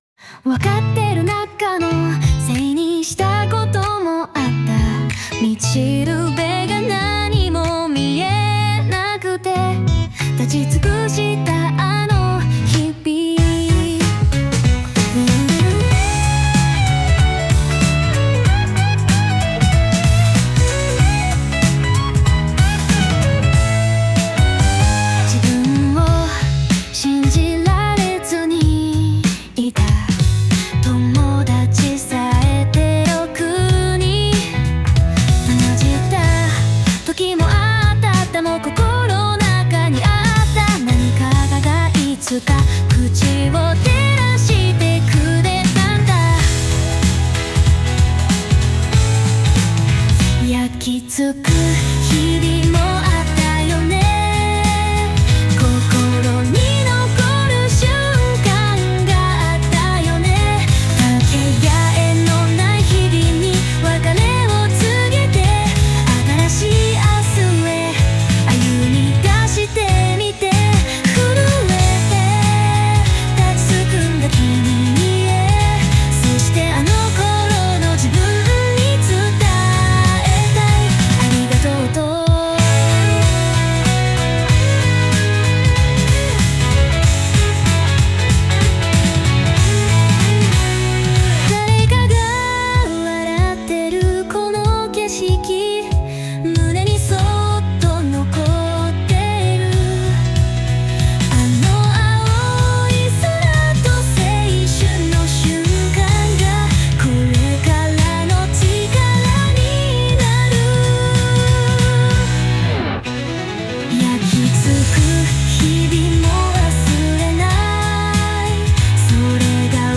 女性ボーカルの歌になります。
卒業用の日本語の歌です。